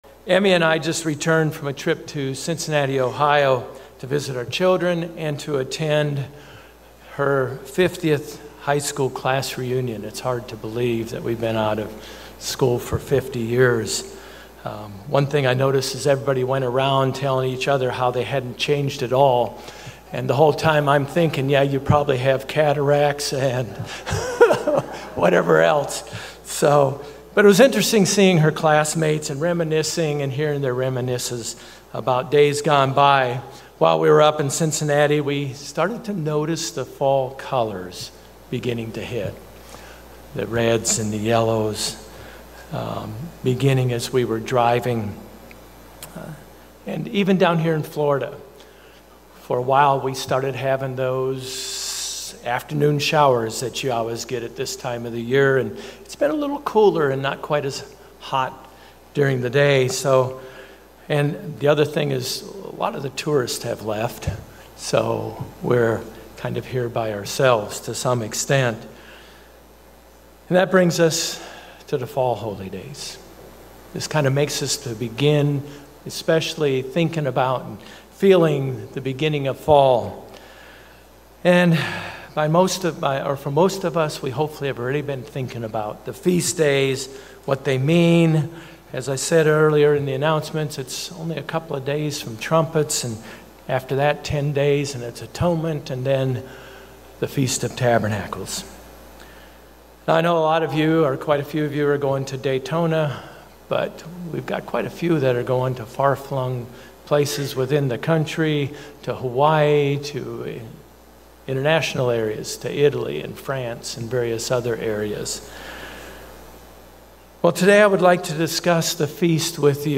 The sermon also presents four “dares” concerning the Feast to help us have a more profitable Feast.